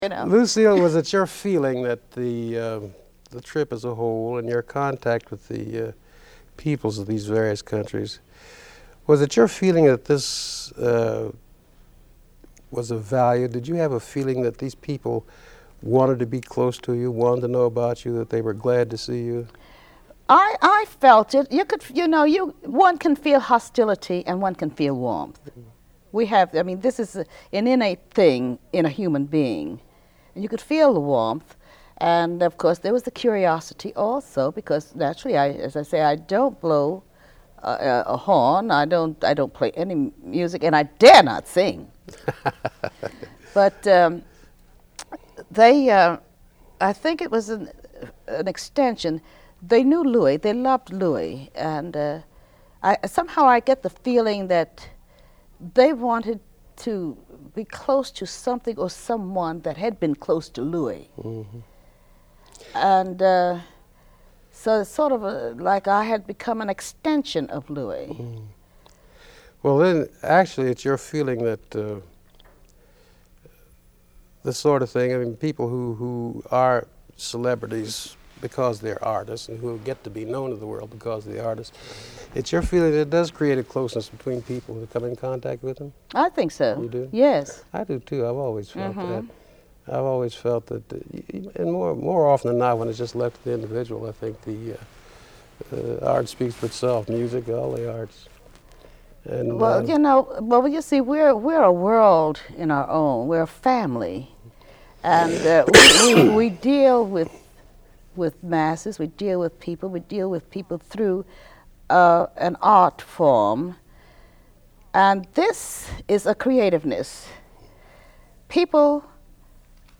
Click here to create a free account and listen to the rest of this interview on our Digital Collections site